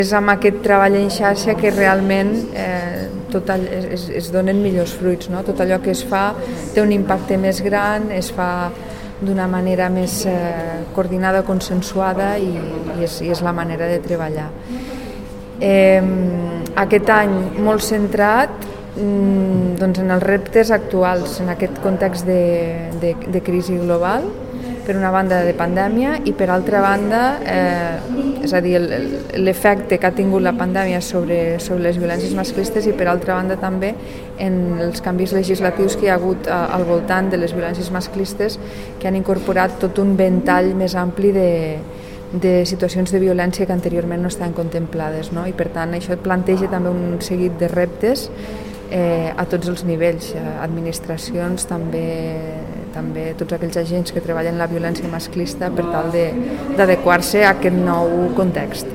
tall-de-veu-de-la-tinent-dalcalde-sandra-castro-sobre-la-presentacio-del-x-memorial-hortensia-alonso-veiga